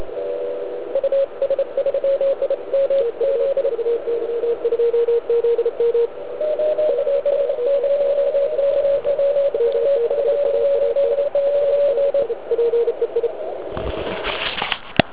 Band je klasicky "panelákově zaprskán asi na S9.